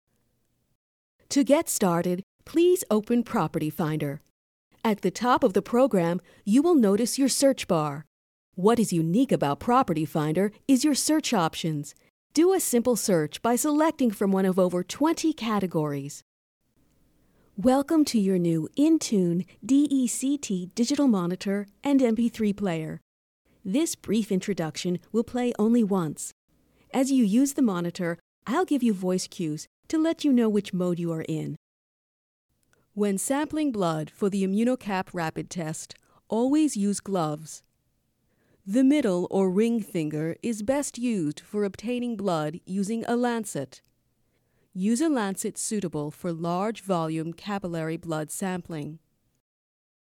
Real enough to sound natural yet trained enough to sound professional.
Versatile, friendly and elegant.
Sprechprobe: Industrie (Muttersprache):
My work always features a neutral accent, clear pronunciation and natural expression.